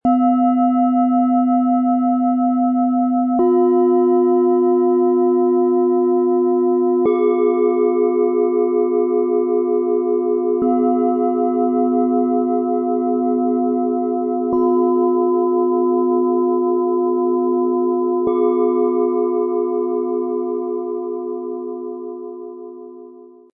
Spüre Leichtigkeit, bleib flexibel, entdecke Neues - Set aus 3 Planetenschalen zum Entspannen und für Klangmeditationen, Ø 13,7 -16 cm, 1,84 kg
Tauche in ihre harmonischen Klänge ein.
Tiefster Ton: Delfin
Mittlerer Ton: Wasser
Höchster Ton: Uranus
MaterialBronze